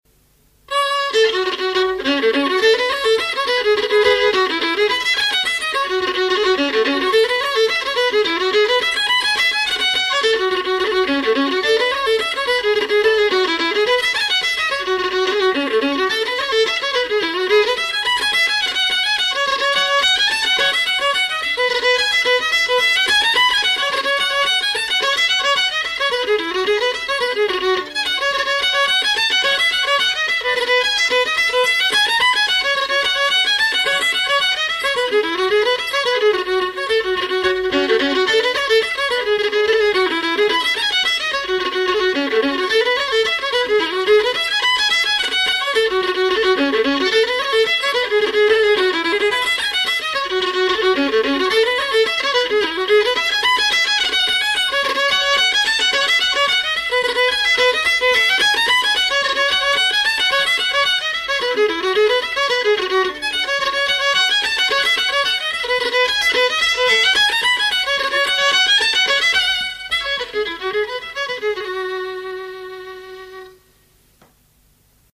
MP3s encoded from a 1988 cassette recording
F# minor reel